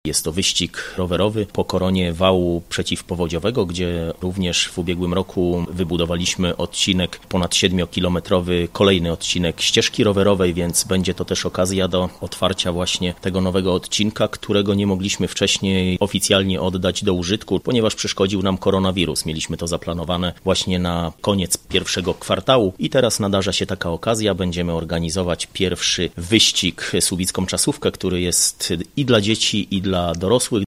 – Trasa prowadzić będzie po niedawno oddanej do użytku drodze asfaltowej na wale oraz szutrówką zlokalizowaną poniżej wału – informuje Mariusz Olejniczak, burmistrz Słubic.